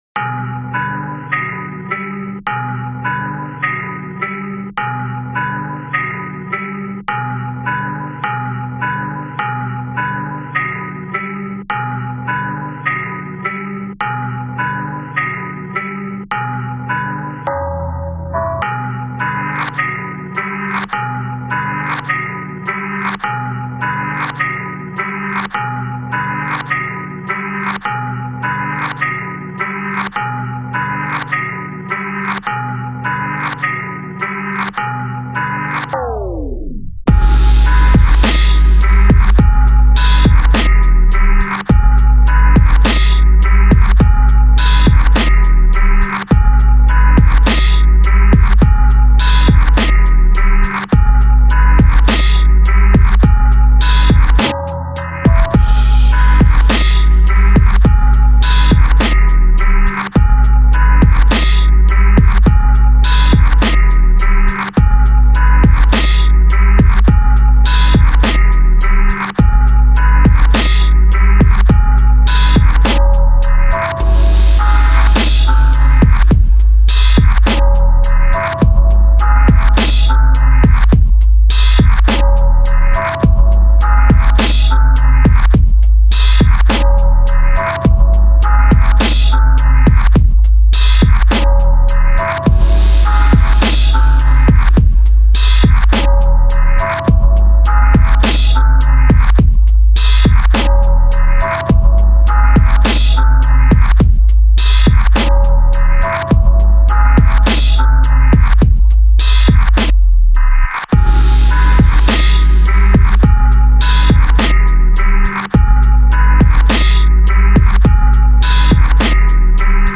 This is the official instrumental
Rap Instrumental